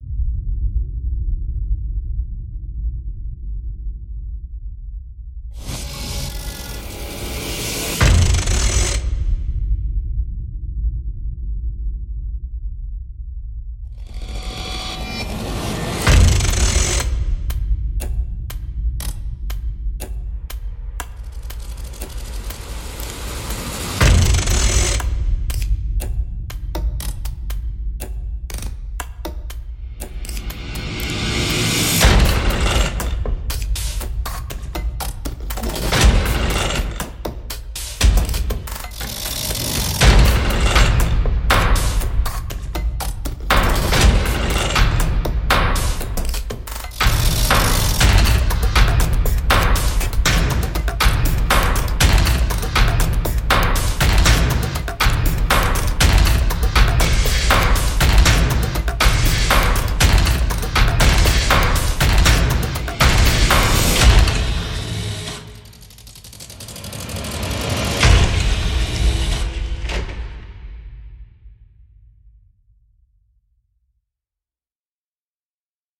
PATTERNS 提供了一个 6 步的音序器，每一步可以从五种类别（点击、机械、水流、音调和金属）中加载不同的声音样本，共有近 350 种独特的样本。
HITS 提供了 80 种不同的嗖嗖声效果，都是由原始录音和近 350 种不同的单击样本组合而成的，在我们的三层引擎中生成打击和特效。
Fallout Music Group Steampunk 是一款适合各种风格的作曲家的创意工具，它可以让你用钟表、曲柄、杠杆、金属、蒸汽、引擎、机器、滴答声等创造出独一无二的声音和氛围。